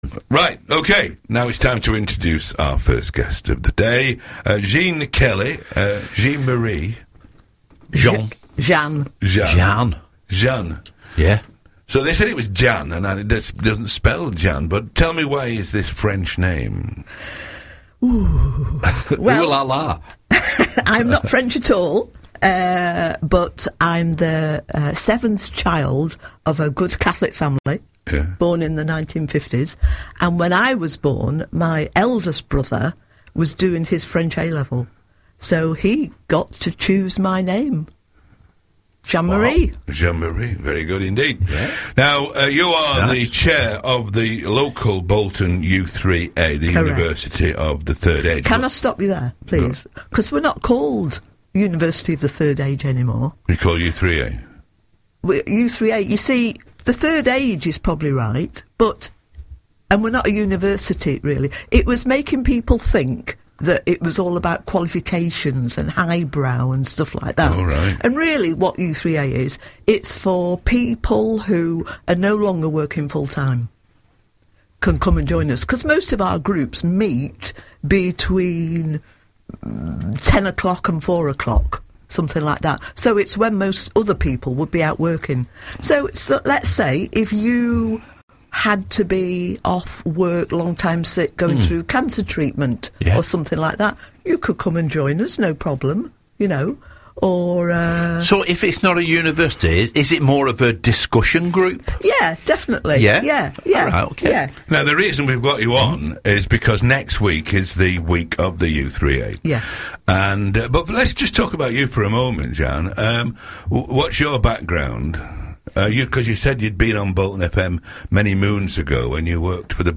Listen to our Chair's latest interview with local radio station Bolton FM